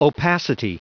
Prononciation du mot opacity en anglais (fichier audio)